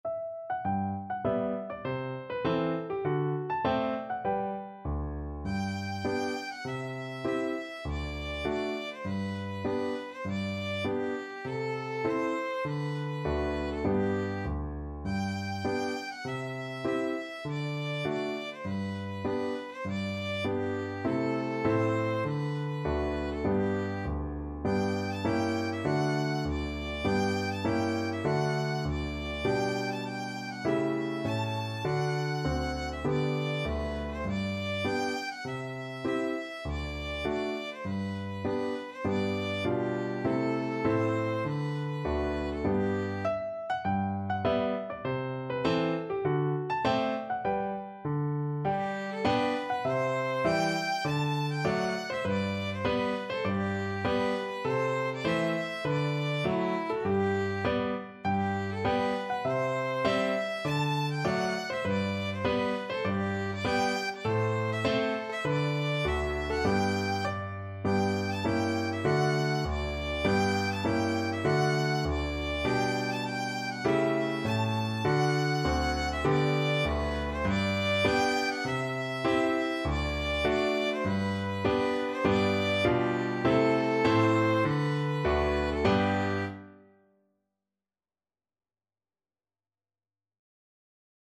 Violin
G major (Sounding Pitch) (View more G major Music for Violin )
Moderato
Traditional (View more Traditional Violin Music)